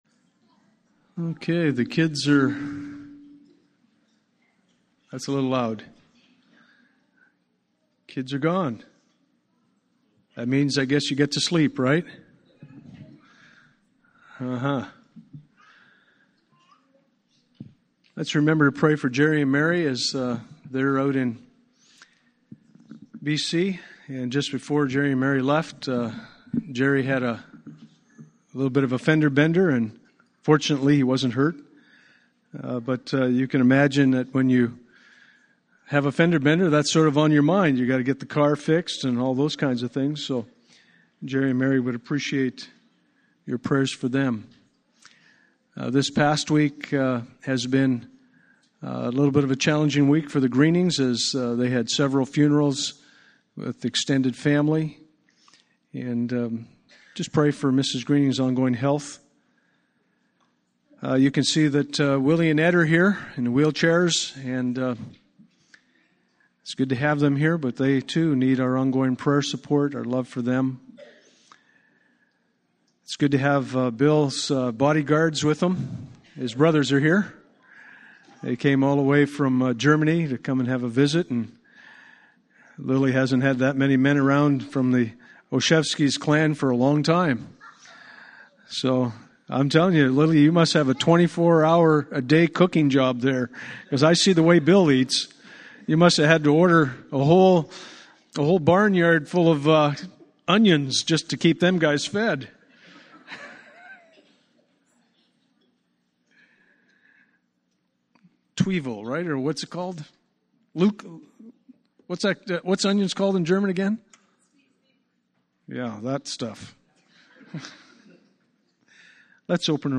Passage: Matthew 8:18-27 Service Type: Sunday Morning « Advent Through the Eyes of the Prophets Just Men